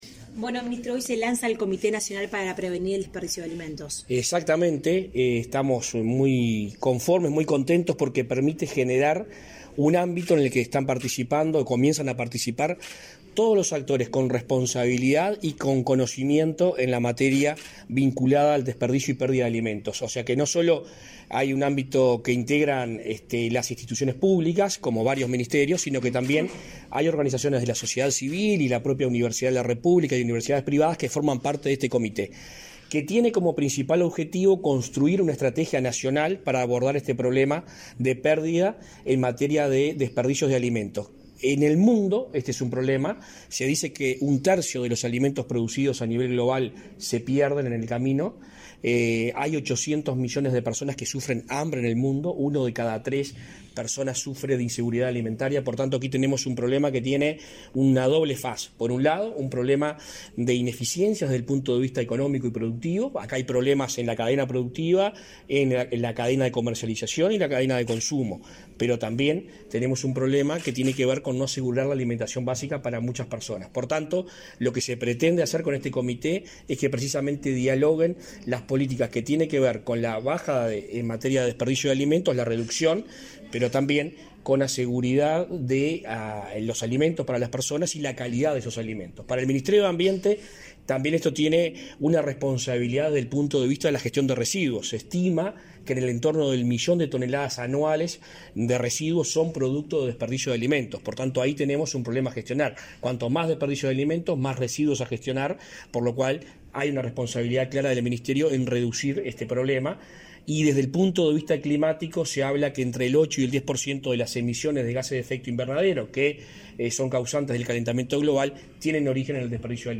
Declaraciones de prensa del ministro de Ambiente, Adrián Peña
Tras participar en la presentación del Comité Nacional para la Prevención y Reducción de las Pérdidas y los Desperdicios de Alimentos (PDA) en Uruguay